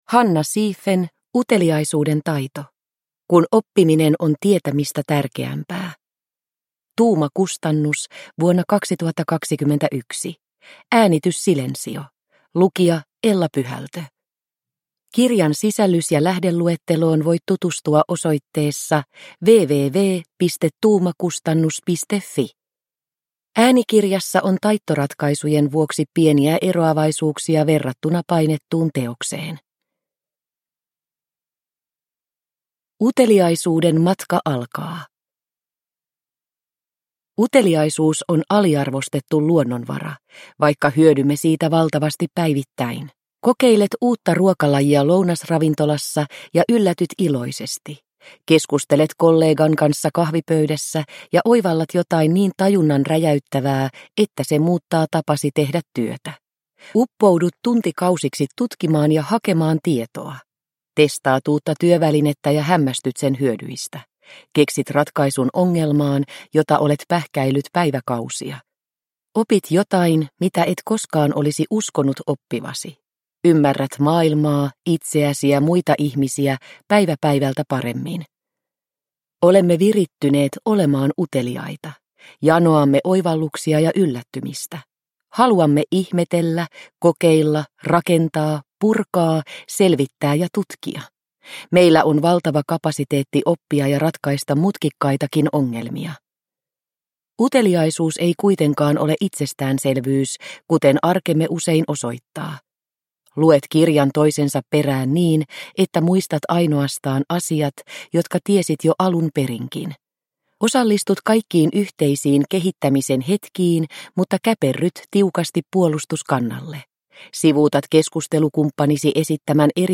Uteliaisuuden taito – Ljudbok – Laddas ner